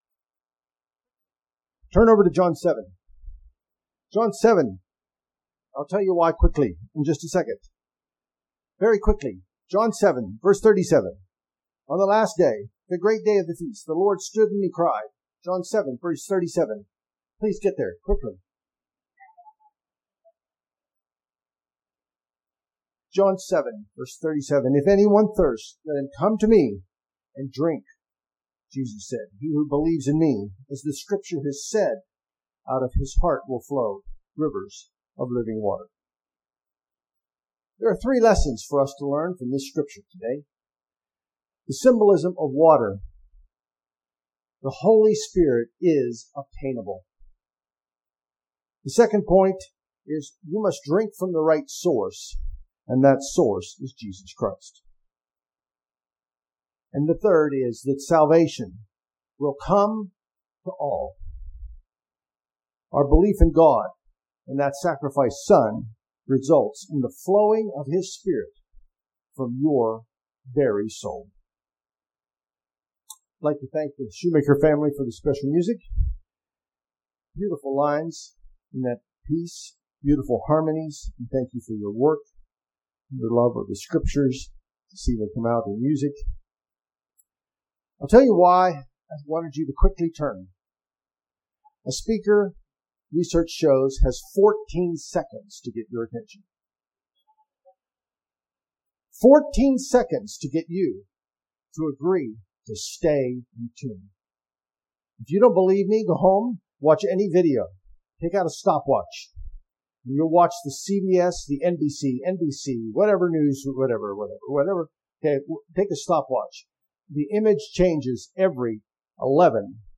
This sermon was given at the Lancaster, Pennsylvania 2020 Feast site.